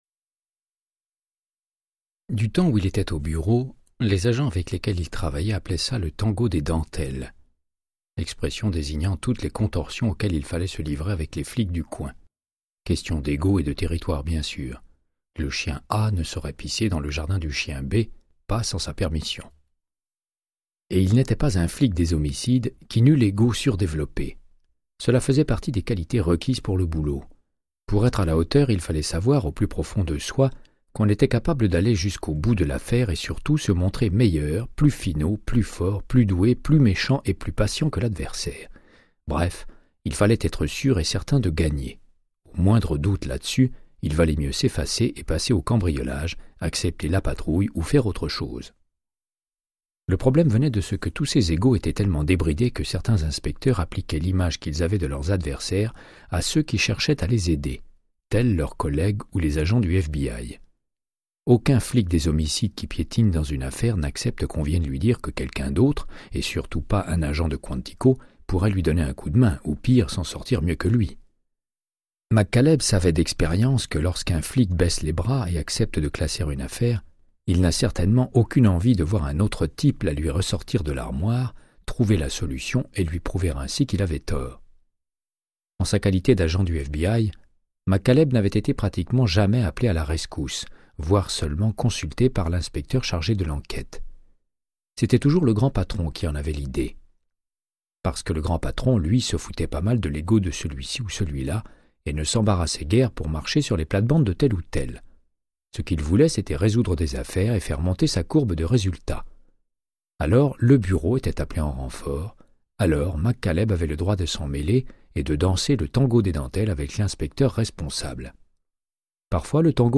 Audiobook = Créance de sang, de Michael Connellly - 12